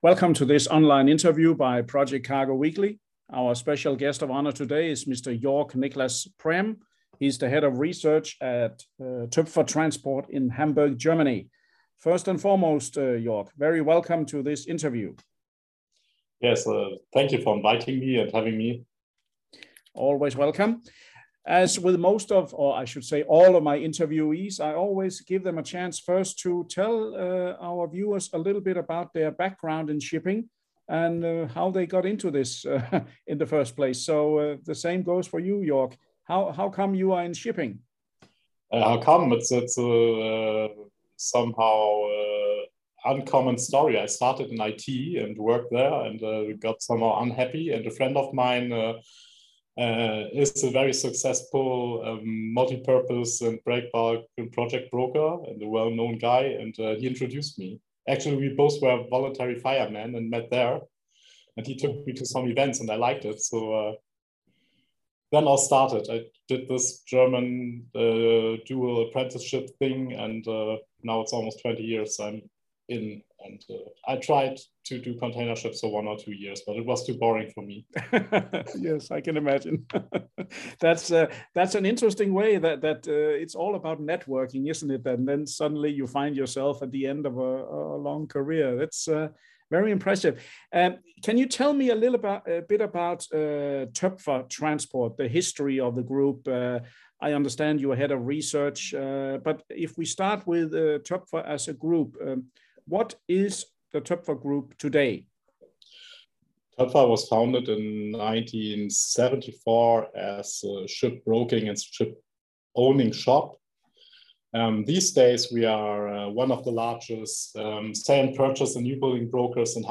Video InterviewToepfer Transport